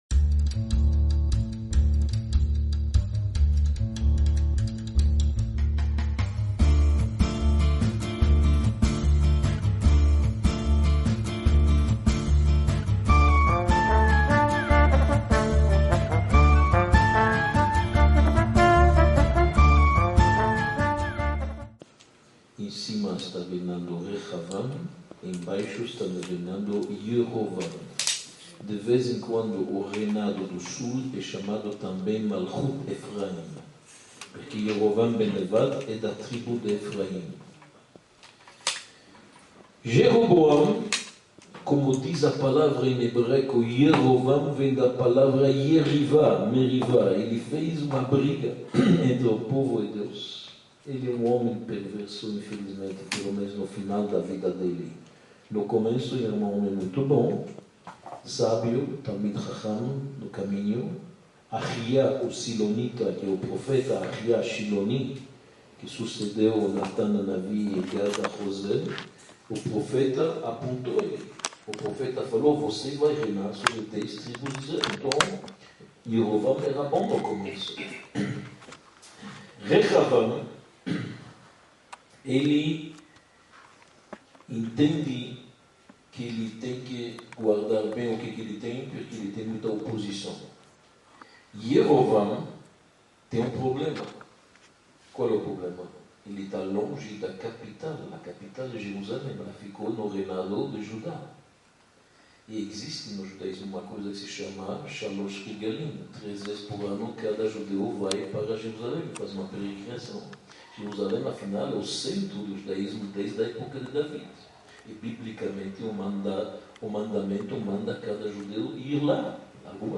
06 – A Cisão: divisão do reino | Módulo I, Aula F | A História Desconhecida do Povo Judeu